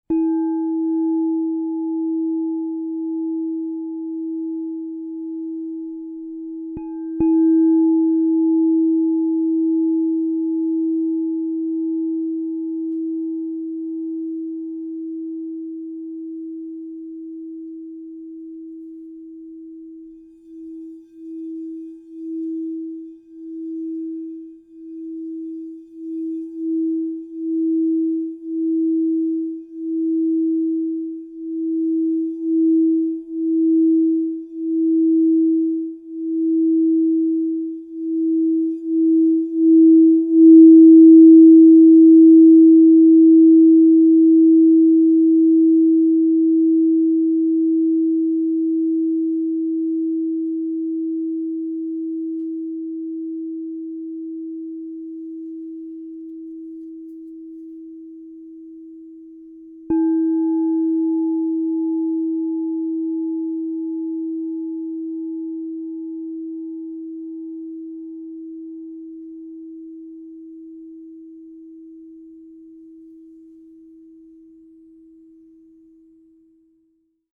Egyptian Blue, Platinum, Ocean Gold (Inside) 8" E -30 Crystal Tones Singing Bowl
This 8" instrument offers a shimmering yet grounded voice, shaped by the unique interplay of inner and outer alchemies. Tuned to –30 cents, this bowl resonates in the 432 Hz spectrum, giving the tone a naturally calming, heart-aligned quality while maintaining clarity from its Platinum overtones and flowing warmth from the Ocean Gold interior. The result is a multidimensional sound field that feels both ancient and elegantly modern, carrying the signature purity of Crystal Tones® craftsmanship.
Its resonance moves like a gentle cosmic tide, guiding practitioners into deeper alignment with their lineage memory, inner authority, and the soothing intelligence of the Hathorian current.
432Hz (-)